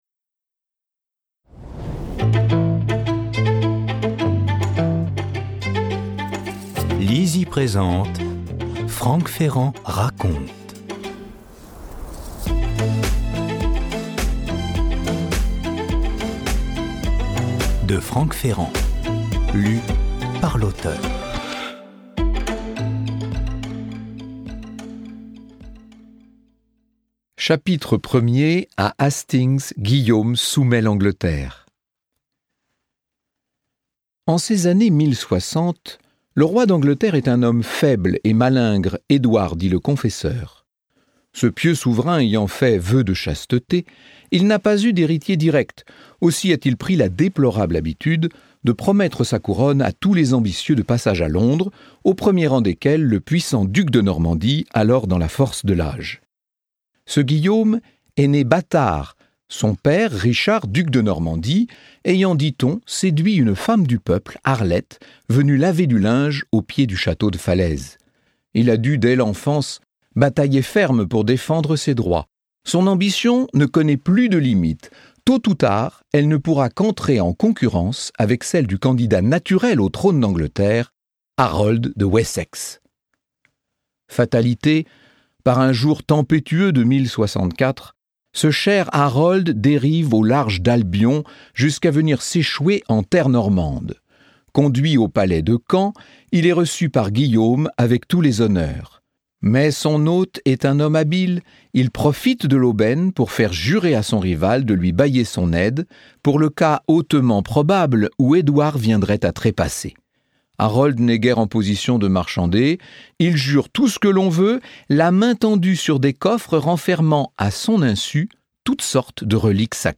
Extrait gratuit - Franck Ferrand raconte de Franck Ferrand